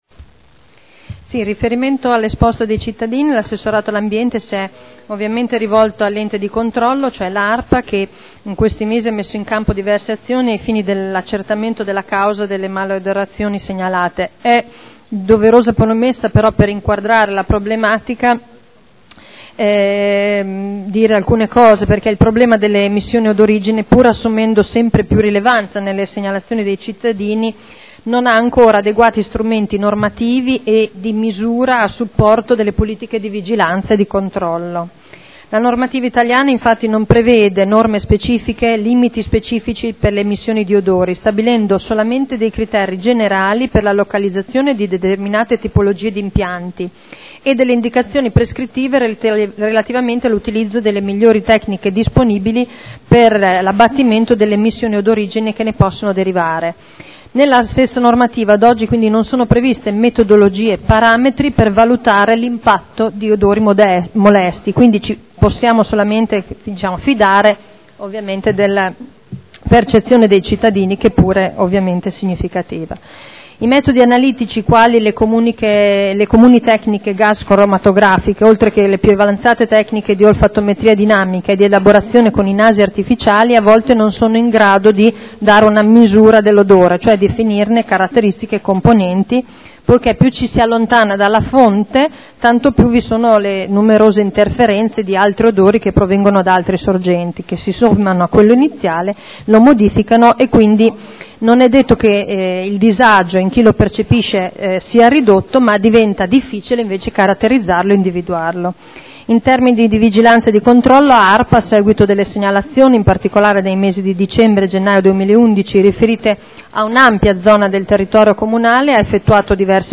Seduta del 30/05/2011. Risponde a interrogazione del consigliere Ricci (Sinistra per Modena) avente per oggetto: “Esalazione nell’aria”